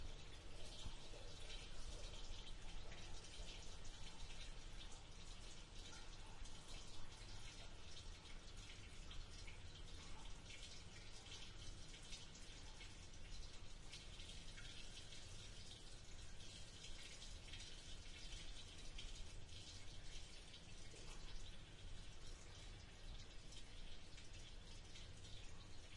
描述：录下自己在一个大的混凝土地下室中奔跑的过程。立体声录音，在我跑开或跑向麦克风的时候，麦克风在一个固定的位置。索尼PCMD50
标签： 地下室 混凝土 现场记录 足迹 脚步声 快点 混响 运行 运行
声道立体声